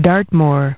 Transcription and pronunciation of the word "dartmoor" in British and American variants.